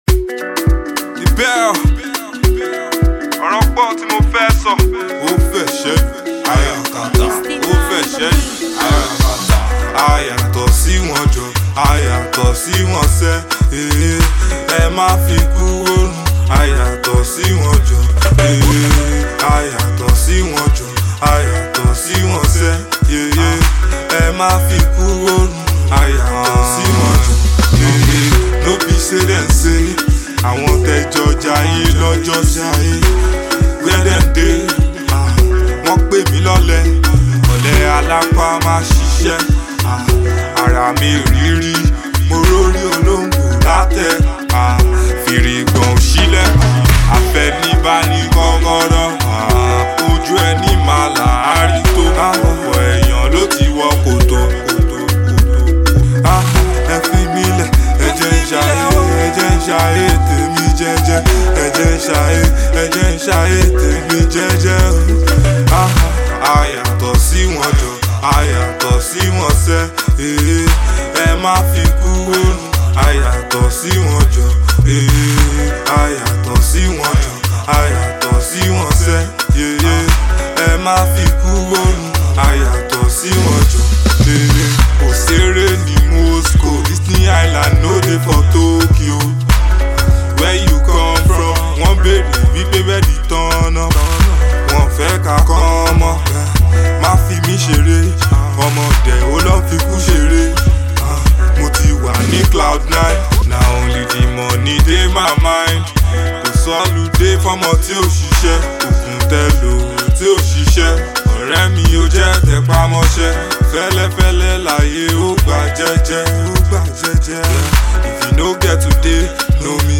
street vibe